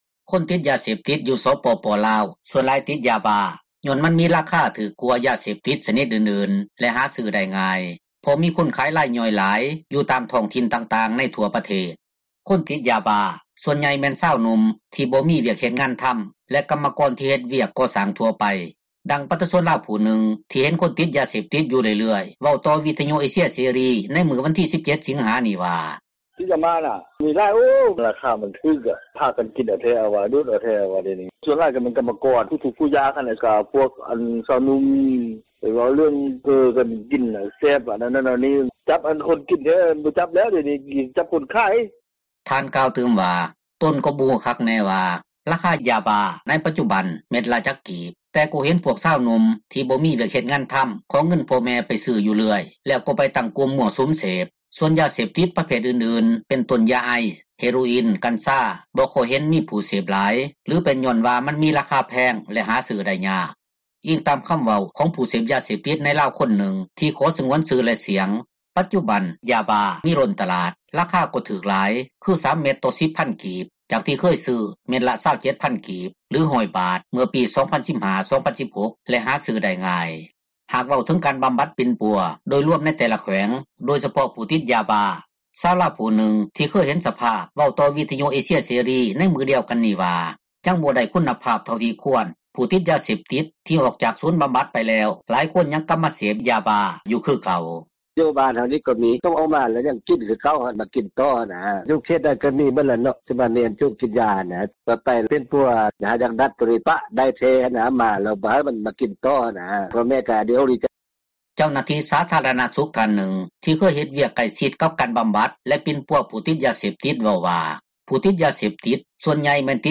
ຄົນຕິດຢາບ້າສ່ວນໃຫຍ່ ແມ່ນຊາວໜຸ່ມ ທີ່ບໍ່ມີວຽກເຮັດງານທໍາ ແລະ ກັມມະກອນທີ່ເຮັດວຽກກໍ່ສ້າງທົ່ວໄປ, ດັ່ງປະຊາຊົນລາວຜູ້ນຶ່ງ ທີ່ເຫັນຄົນຕິດຢາເສບຕິດຢູ່ເລື້ອຍໆ ເວົ້າຕໍ່ວິທຍຸເອເຊັຽເສຣີ ໃນມື້ວັນທີ 17 ສິງຫານີ້ວ່າ: